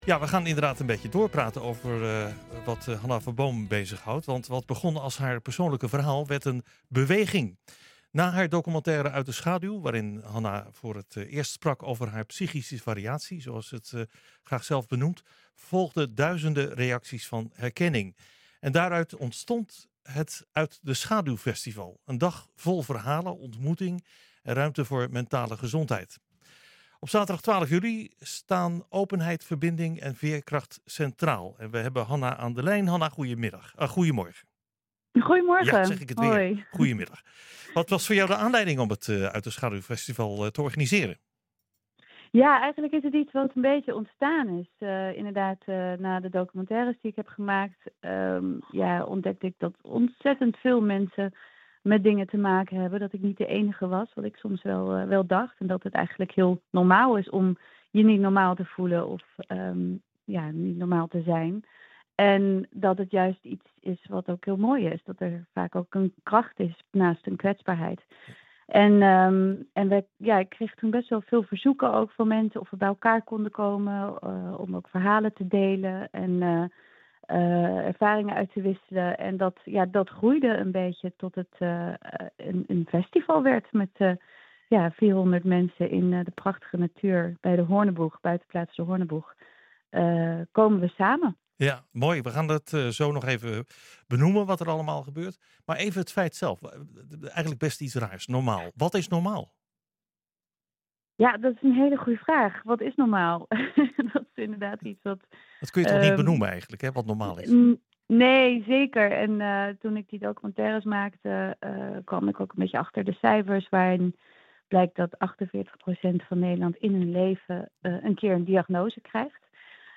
Luister hier naar het interview met Hanna Verboom in NH Gooi Zaterdag